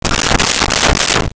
Soap Dispenser 1
soap-dispenser-1.wav